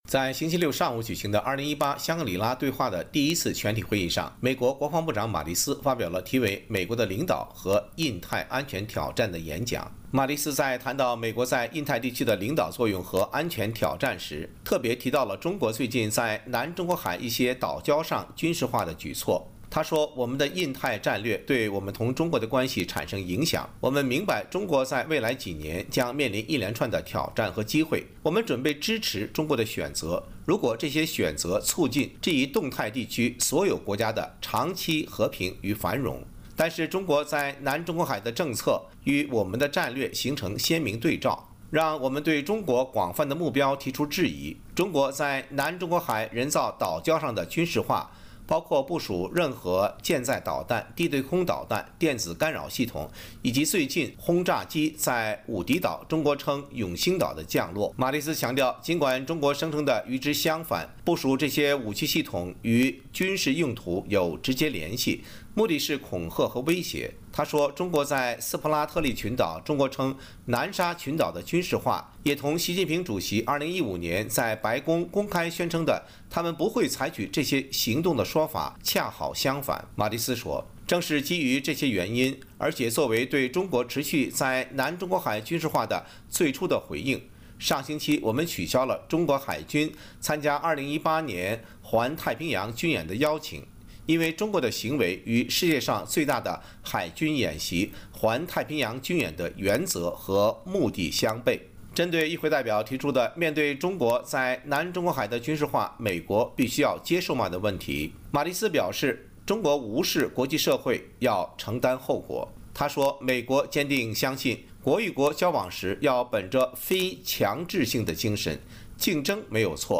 美国国防部长马蒂斯在2018香格里拉对话上发表“美国的领导和印太安全挑战”演讲。
前美国国防部长科恩在接受美国之音专访